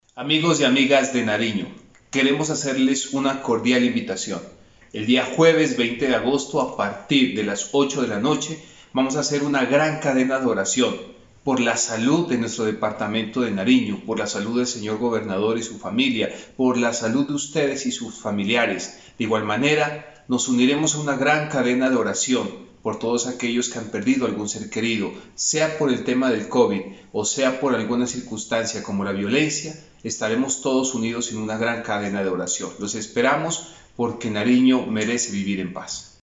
Por su parte, el Subsecretario de Paz y Derechos Humanos del Departamento, Amílcar Pantoja, realizó la invitación para que todos los nariñenses se unan a esta gran jornada de oración por la vida y la paz.